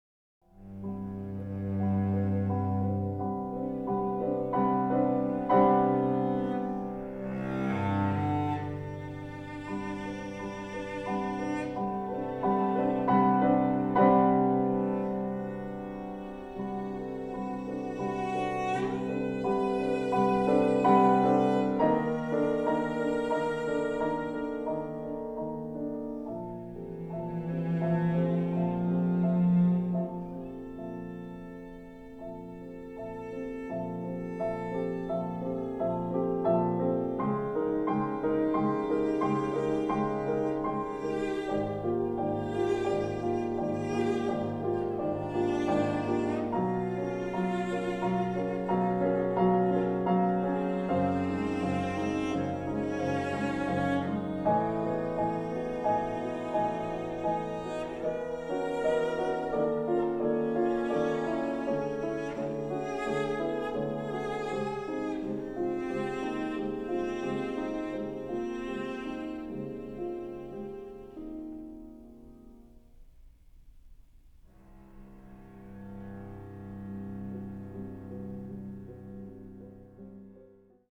violoncello & piano